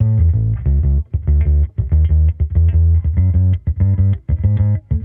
Index of /musicradar/sampled-funk-soul-samples/95bpm/Bass
SSF_PBassProc2_95A.wav